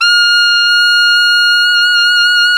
SAX ALTOMF0T.wav